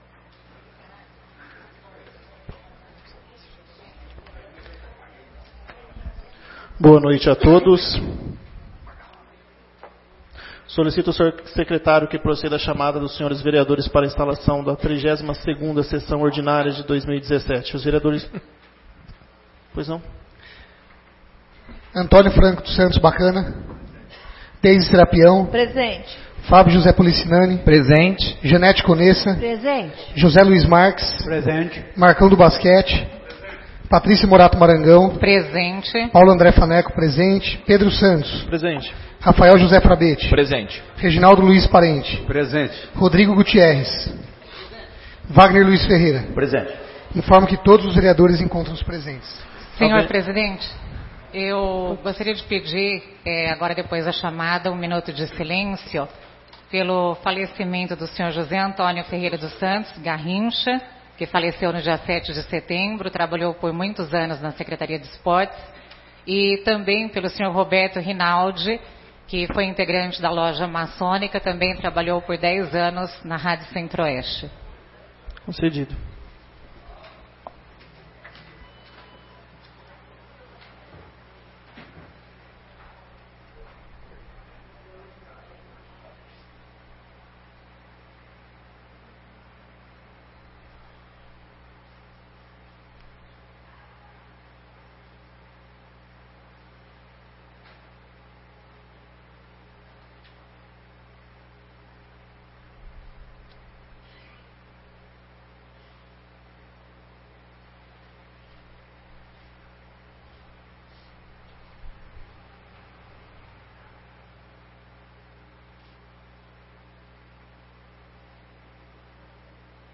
32ª Sessão Ordinária de 2017 — Câmara Municipal de Garça